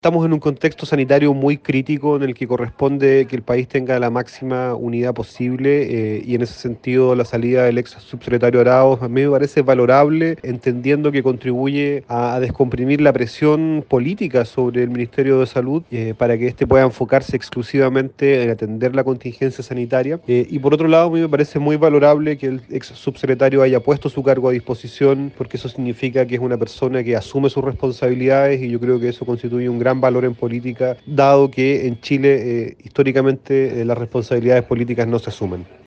Por su parte el Diputado Jaime Sáez, representante del partido Revolución Democrática, también valoró la salida del ex subsecretario Araos: